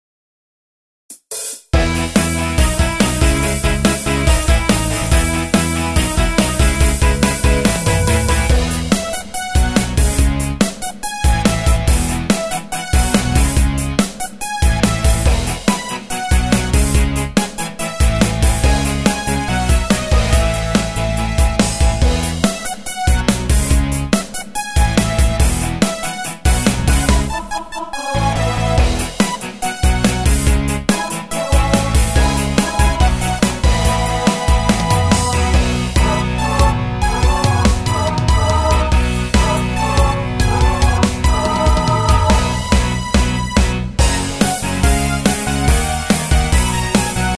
Tempo: 142 BPM.
MP3 with melody DEMO 30s (0.5 MB)zdarma